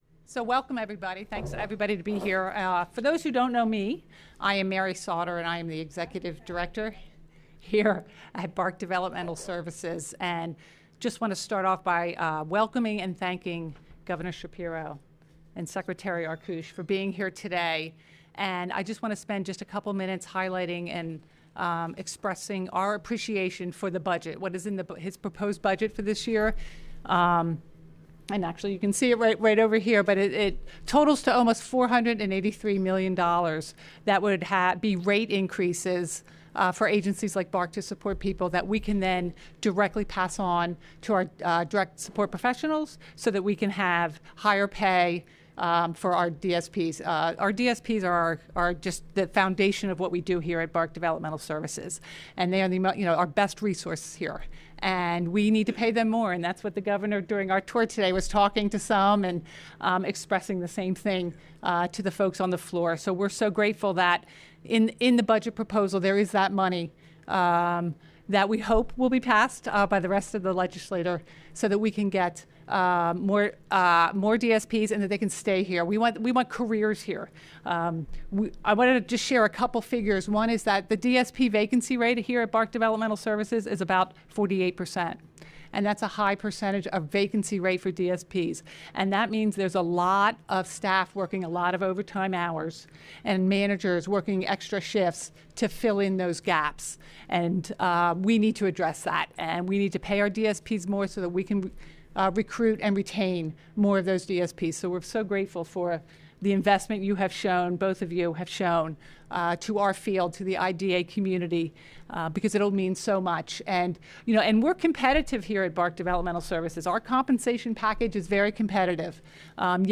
Governor Shapiro, DHS Secretary Arkoosh Hear from Pennsylvanians with ID/A, Caregivers, and Advocates About How Historic Budget Proposal Would Expand ID/A Services
25799_gov_autismRoundtable.mp3